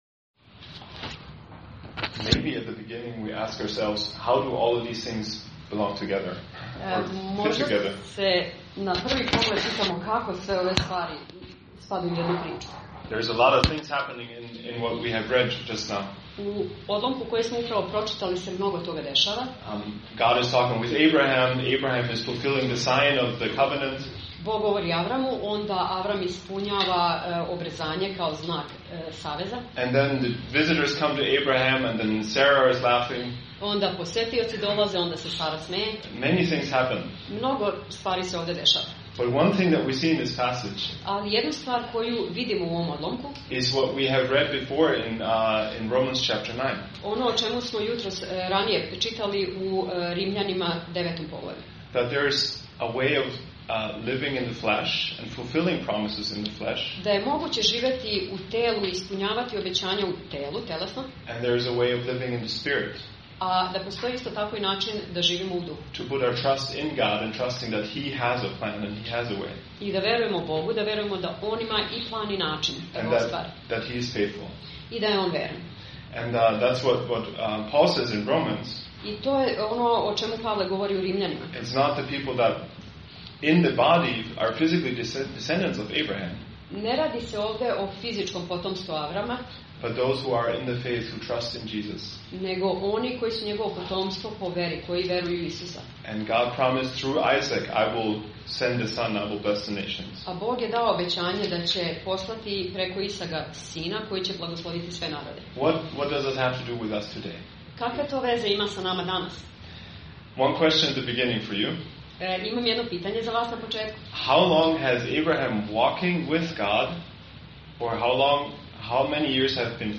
Propoved: Obećanje ponovljeno i objašnjeno - 1. Mojsijeva 17:15-18:15